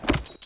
trot3.ogg